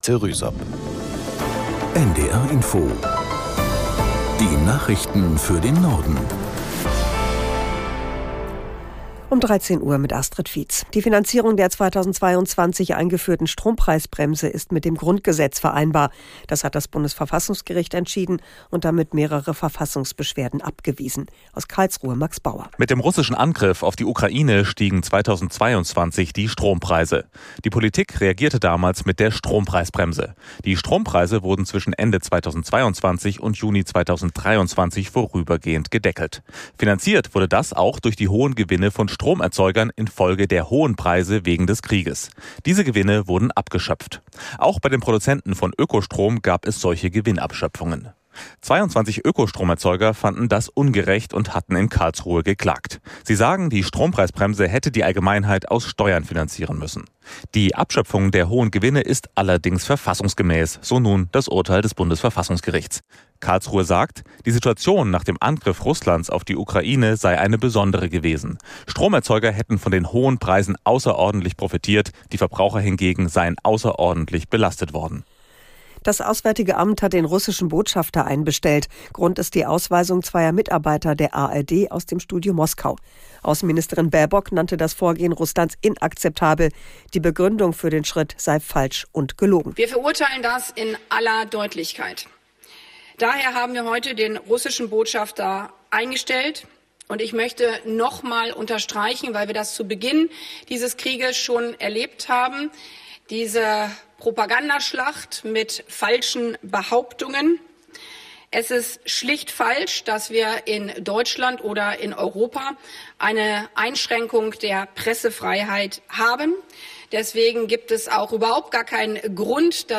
Nachrichten für den Norden.